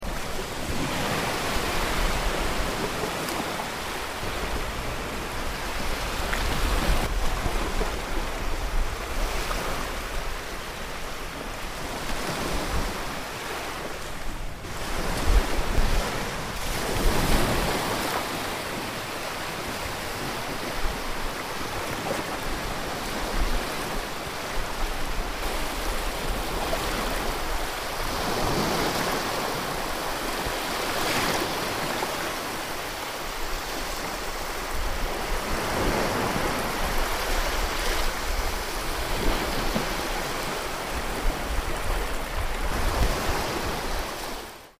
Mare con spiaggia di sabbia
mare e sabbia.mp3